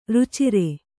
♪ rucire